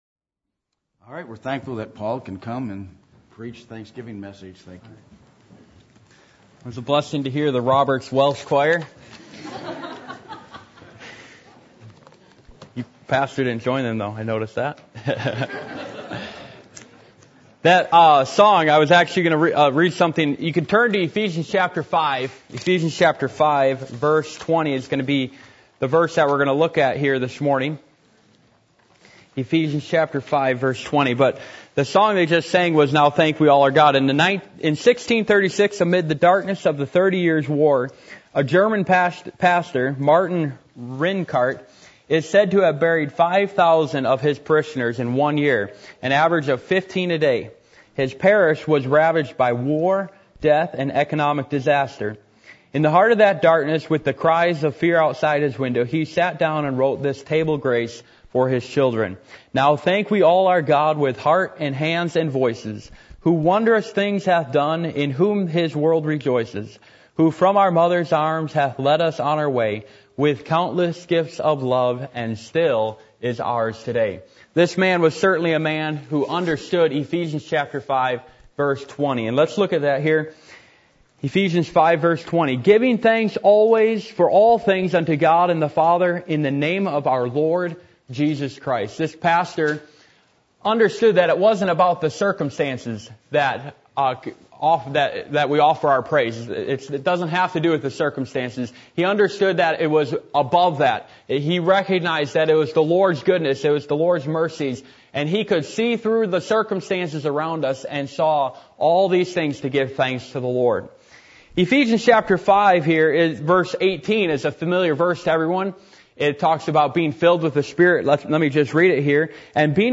Service Type: Thanksgiving